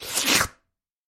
Звуки облизывания
Смачно облизнув